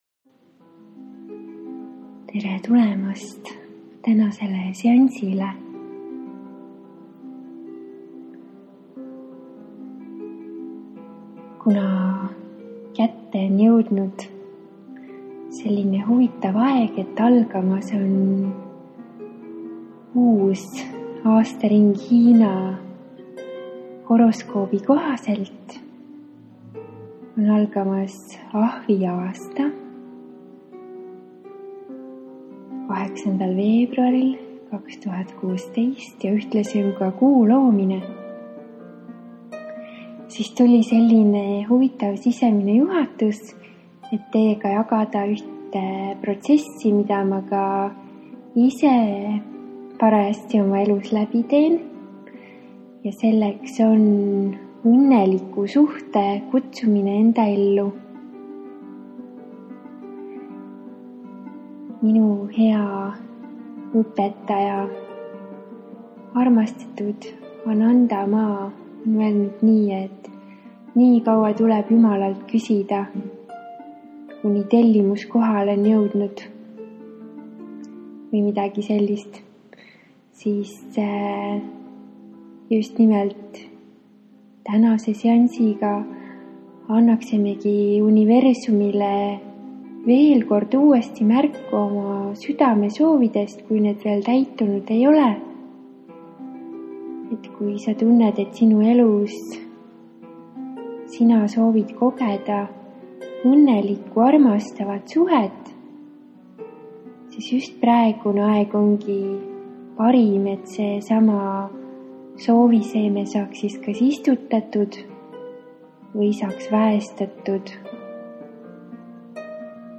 Iga kord seda meditatsiooni kuulates saavad puhastatud Su meel, teadvus, keha ja süda.